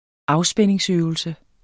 Udtale [ ˈɑwˌsbεnˀeŋs- ]